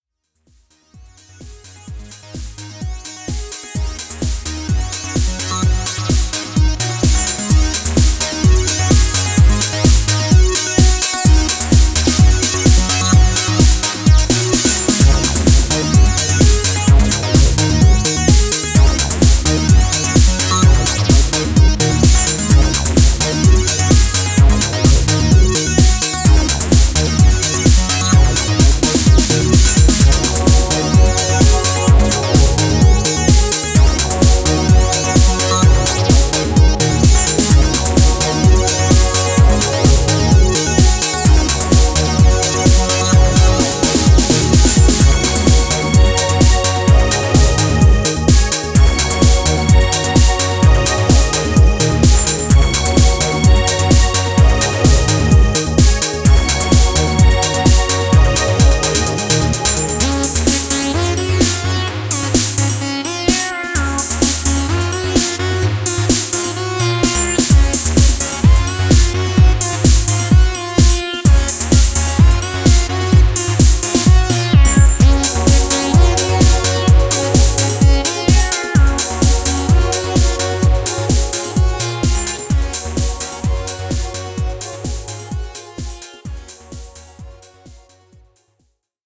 シンセリードが眩い
ニュースクールな地下テック・ハウスを披露しています。